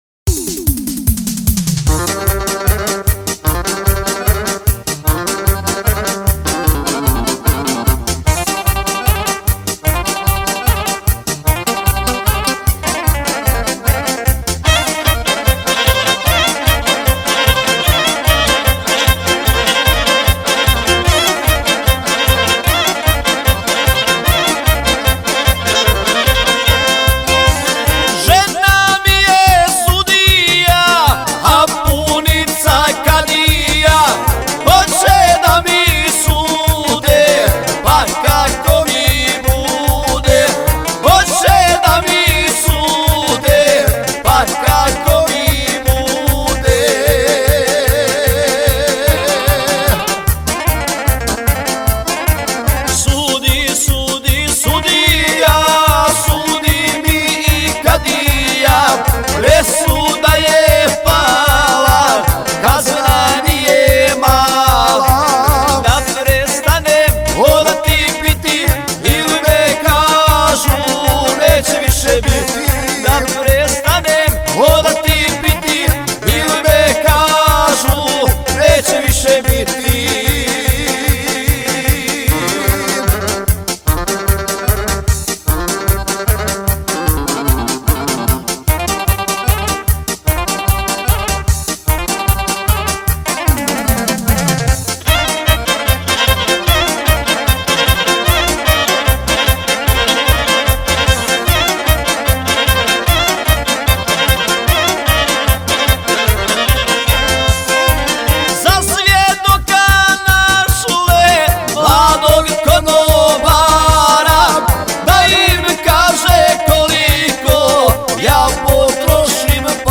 violina i solista
klavijatura i solista